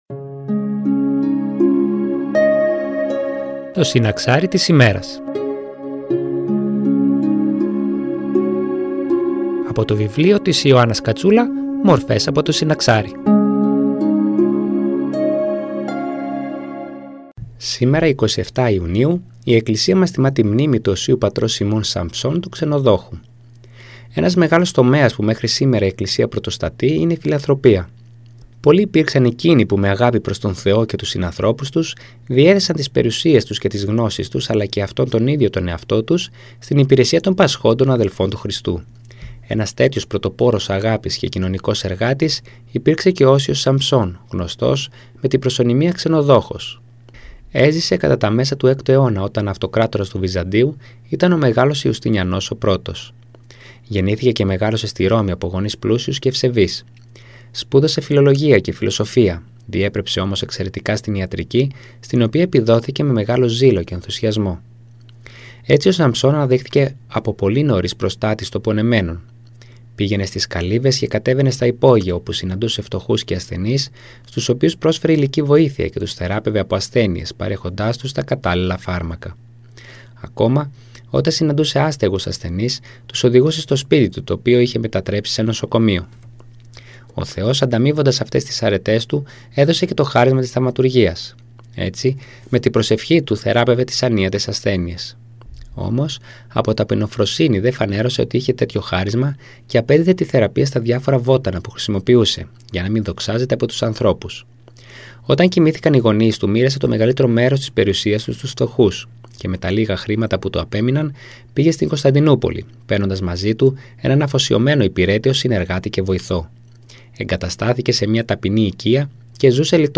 Εκκλησιαστική εκπομπή